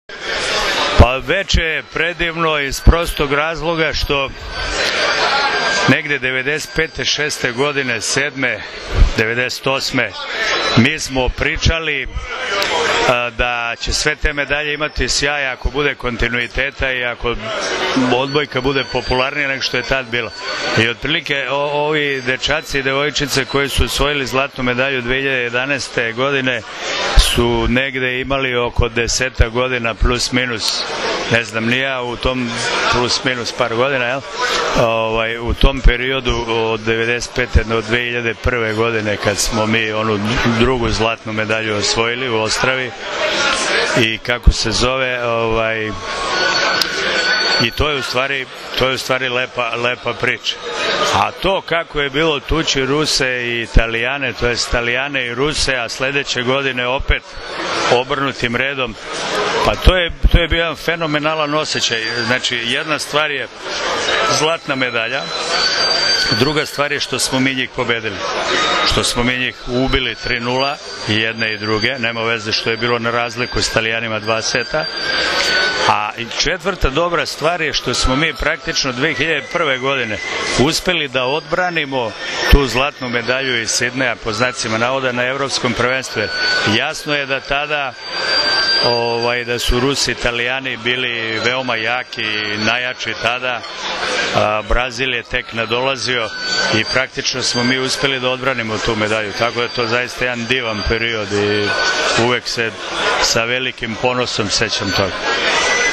Odbojkaški savez Srbije je večeras u beogradskom hotelu „M“ priredio svečanost pod nazivom „Olimpijski kontinutitet“ povodom plasmana ženske i muške seniorske reprezentacije na Olimpijske igre u Londonu.
IZJAVA